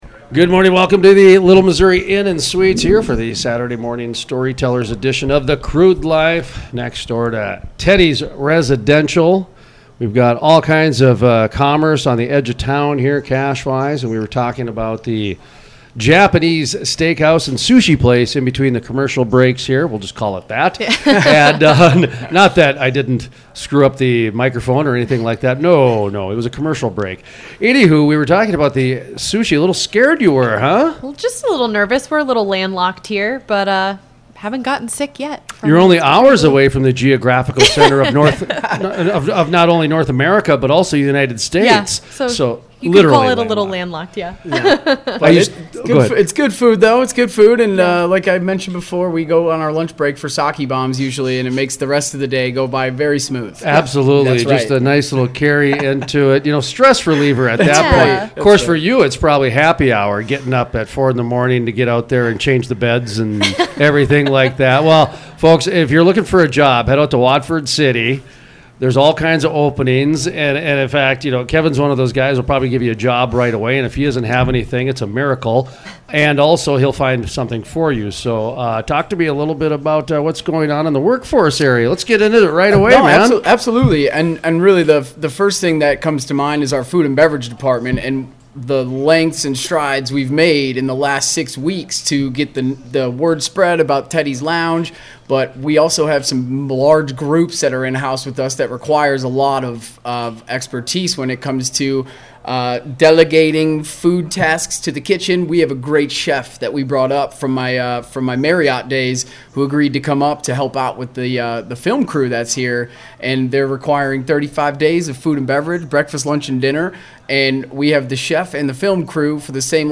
Full Length Interviews